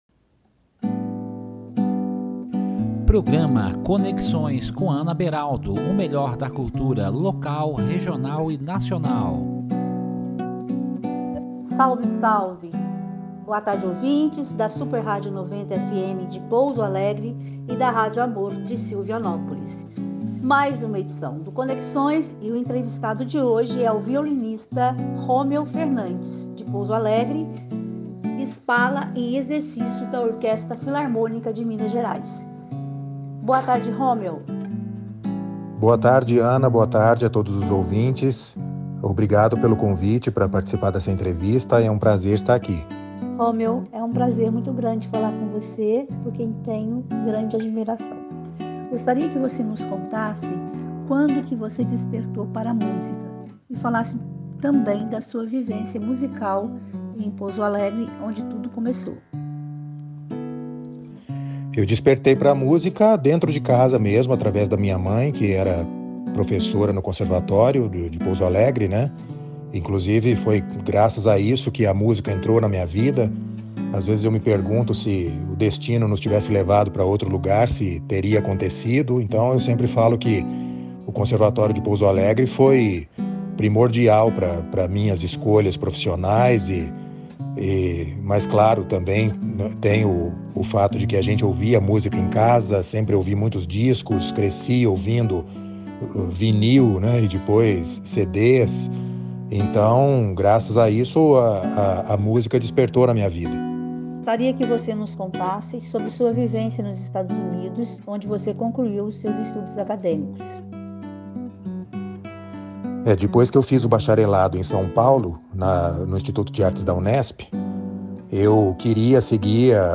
Com ênfase para entrevistas na área cultural, o Programa vai ao ar aos domingos, às 13h30.